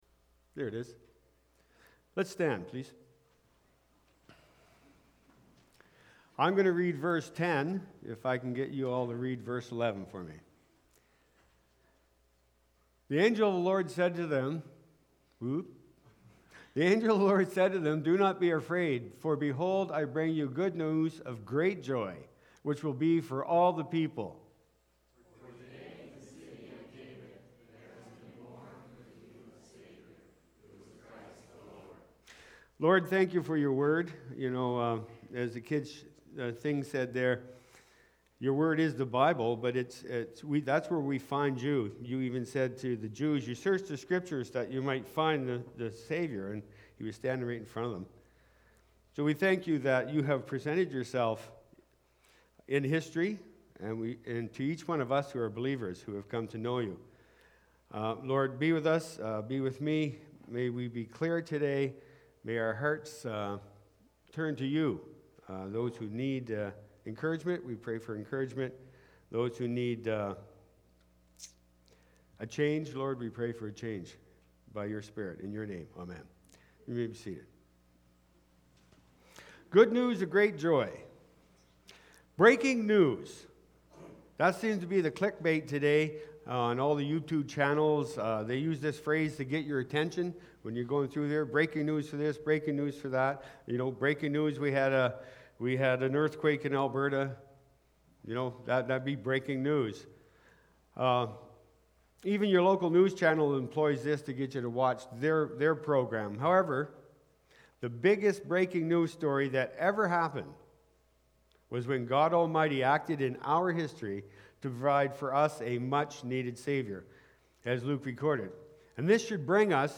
December-4-2022-sermon-audio.mp3